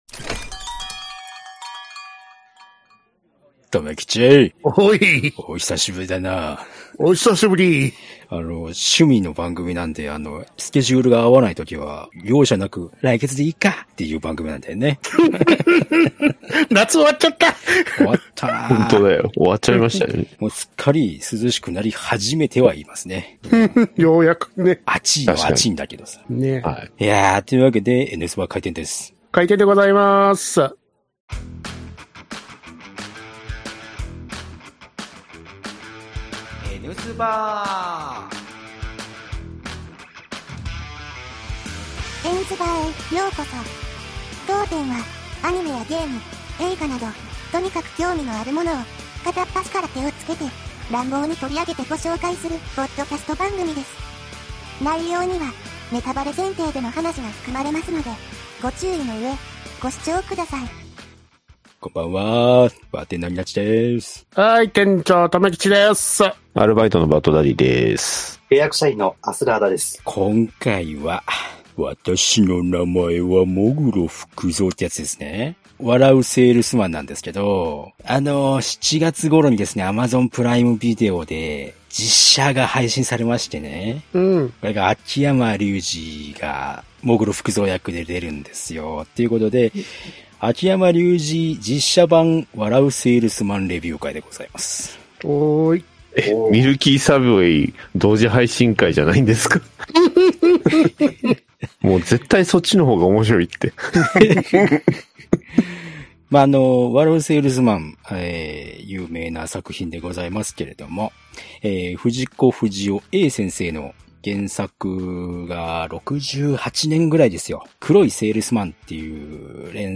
一部音声が聞き取りにくい部分がございます、ご了承をお願いいたします